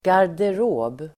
Uttal: [gar_der'å:b]